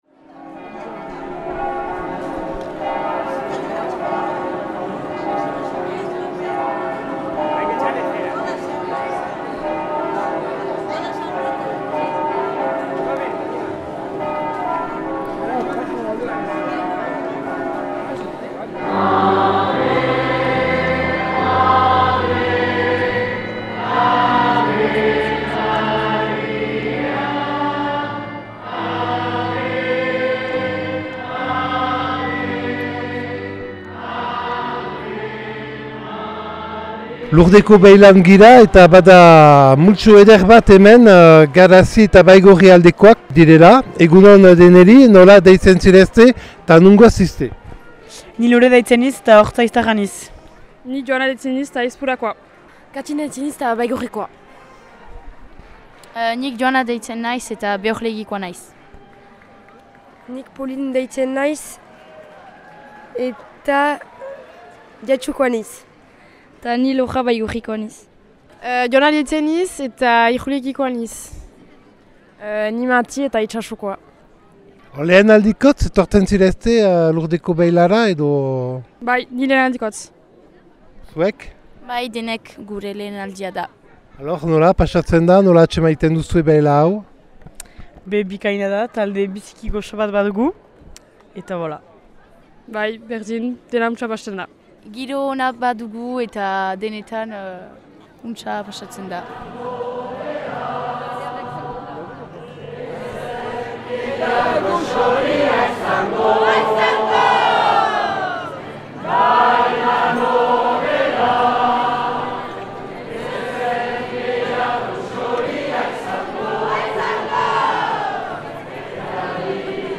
Lekukotasunak Lurdeko elizbarrutiaren beila 2023. irailaren 16an